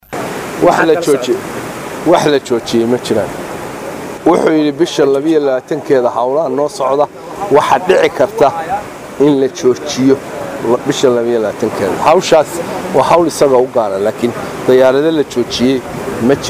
Muqdisho(INO)- Wasiirka Wasaarada duulista iyo gaadiidka Hawada ee Dowlada Federaalka Soomaaliya Cali Jaamac Jingeli oo Warbaahinta kula hadlay Muqdisho ayaa sheegay in aan si toos ah loo joojin duulimaadyadii aadi jiray Magaalada Hargeyso.